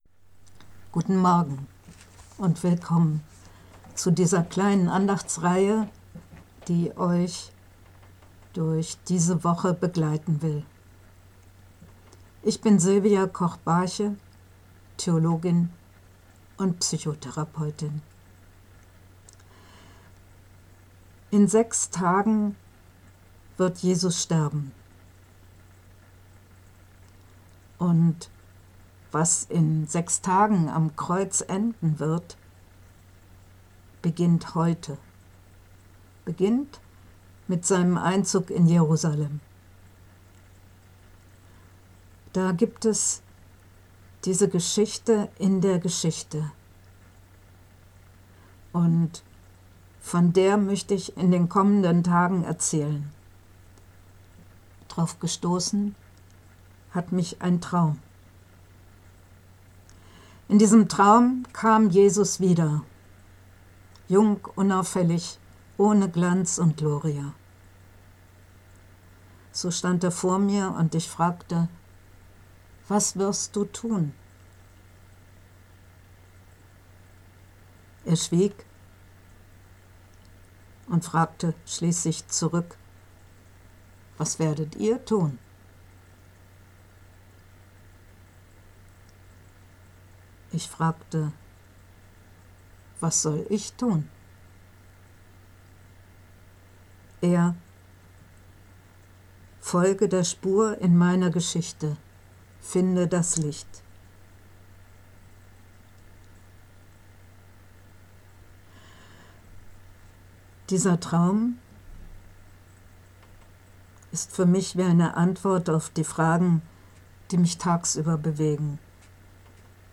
Andacht zum Palmsonntag, 29. März